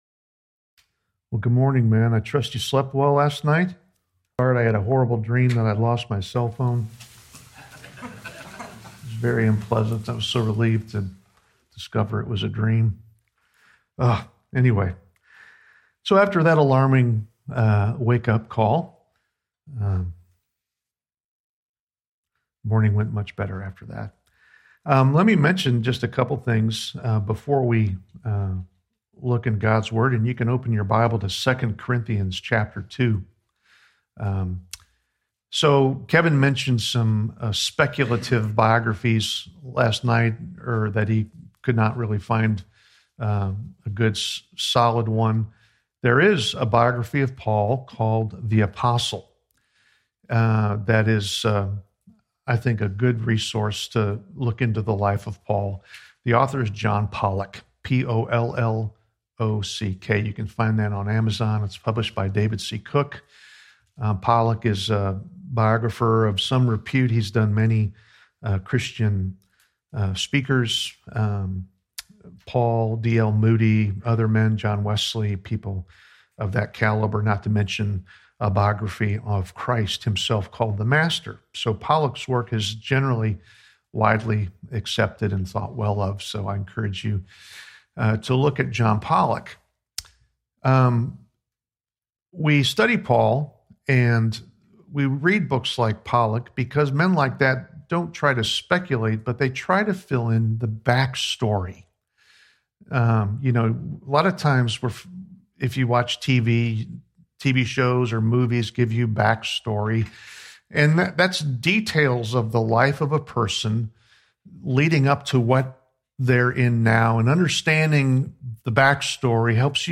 Play the sermon Download Audio ( 27.21 MB ) Email Session 2 - Having Affection for Believers Details Series: General Topics Date: 2019-01-05 Scripture : 2 Corinthians 2:1-4